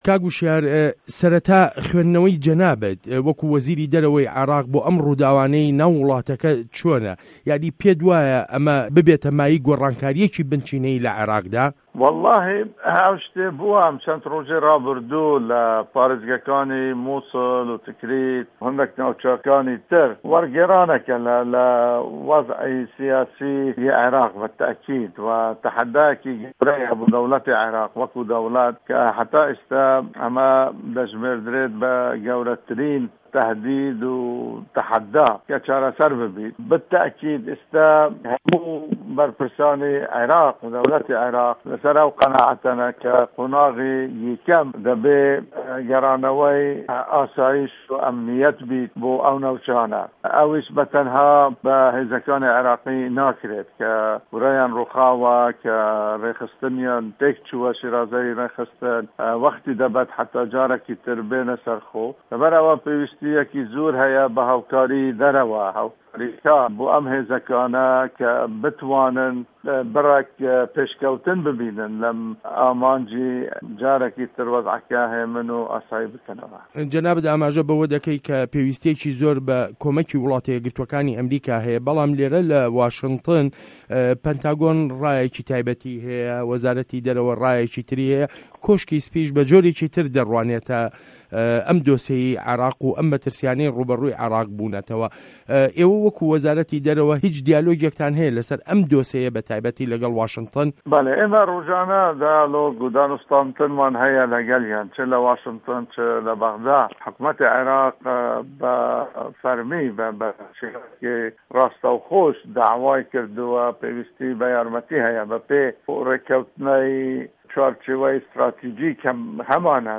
وتووێژ له‌گه‌ڵ هوشیار زێباری